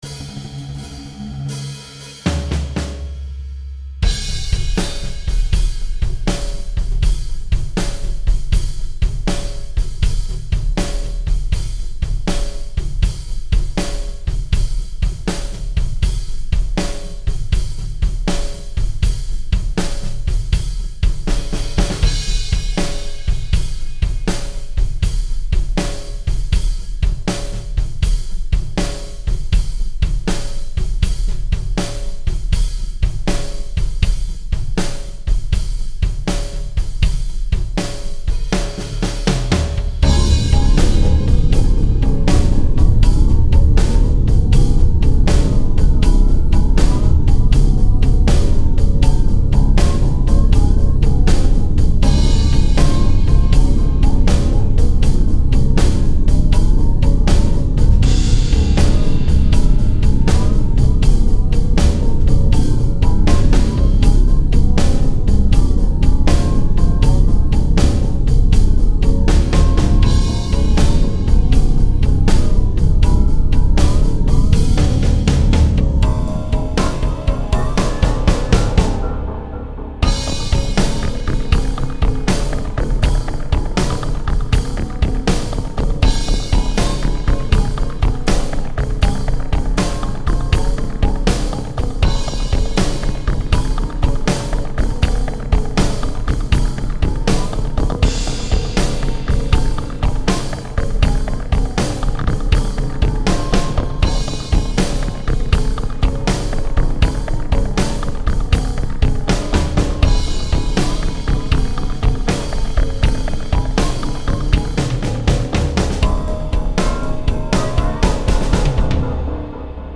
THE PREVIOUS VERSIONS ALBUM WITH ONLY ELECTRONIC SOUND